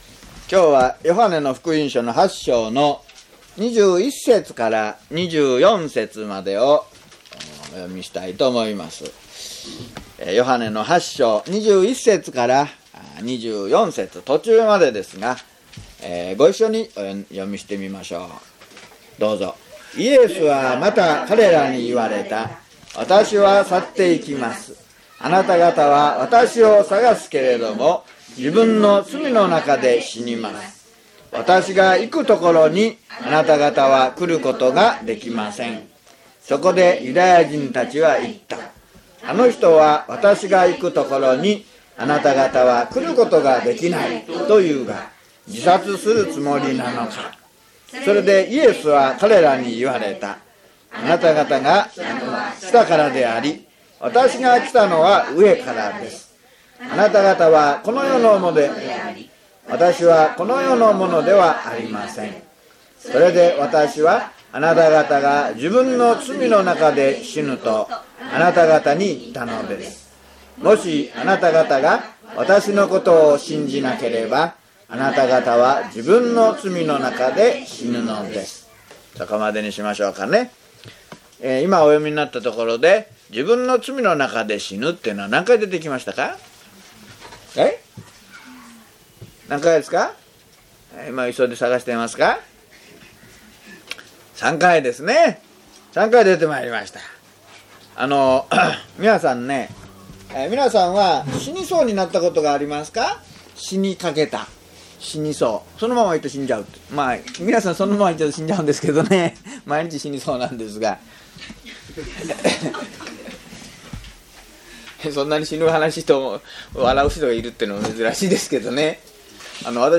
hp_john057mono.mp3